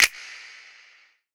Snap (Industrial).wav